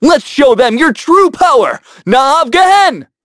Evan-Vox_Skill5_a.wav